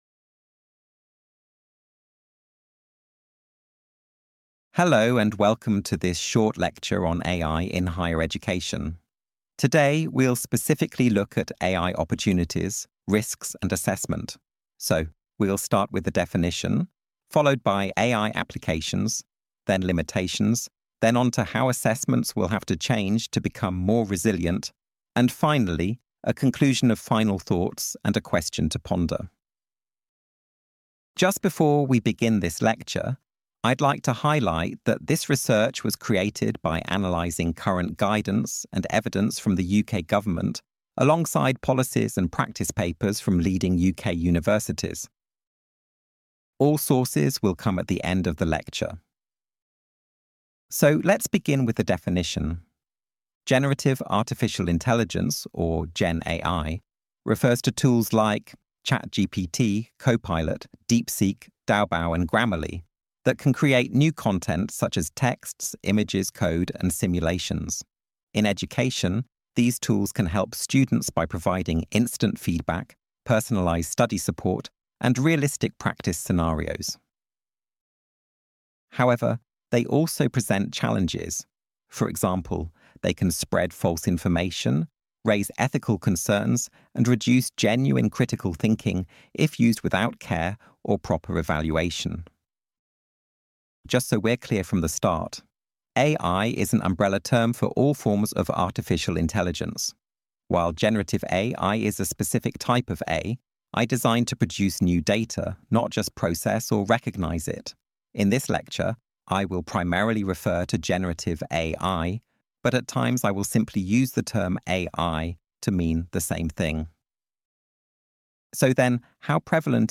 This listening lecture lesson examines how generative AI is reshaping higher education by exploring its opportunities, risks, and implications for assessment design.